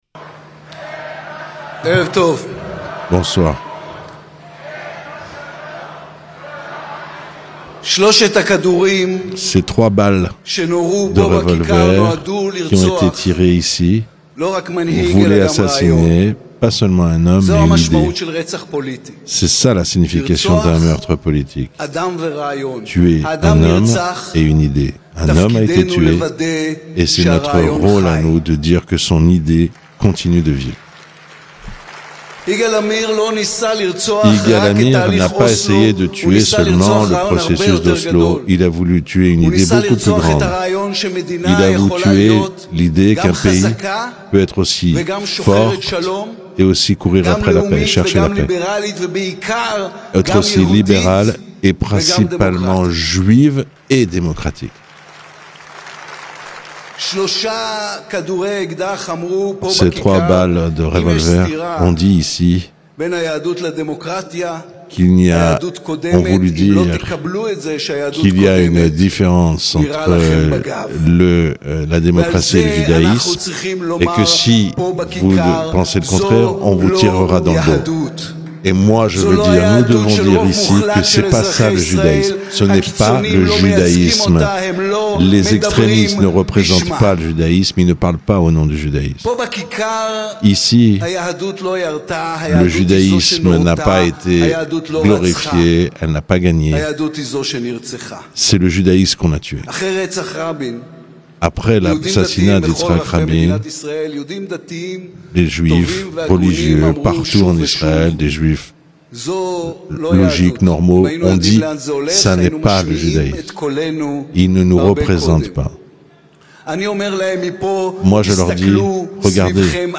Anniversaire de l'assassinat d'Ytzhak Rabin : écoutez le discours de Yaïr Lapid traduit en français
Un rassemblement avec des milliers d'Israéliens s'est tenu devant la mairie pour commémorer l'évènement. Ecoutez le discours prononcé par le leader de l'opposition , Yaïr Lapid.